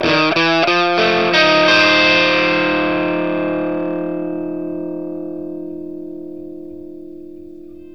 PICK1 F 7 60.wav